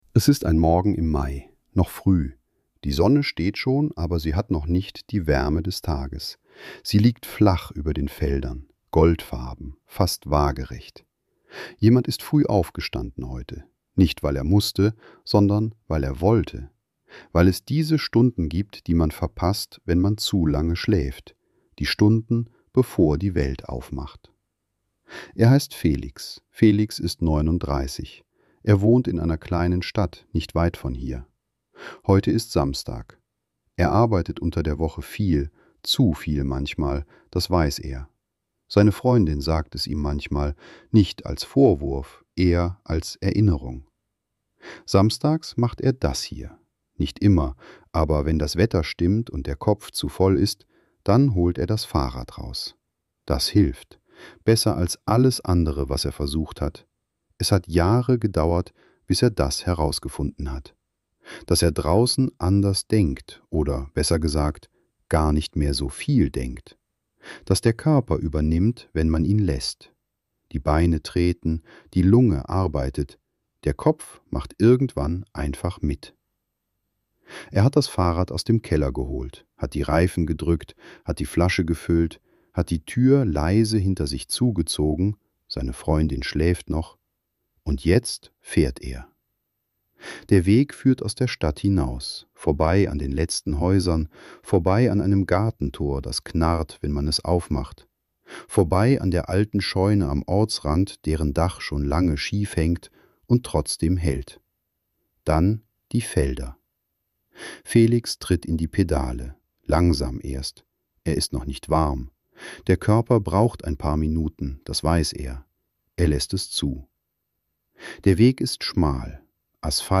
Rapsfelder ~ Stille Orte — Schlafgeschichten für die Nacht Podcast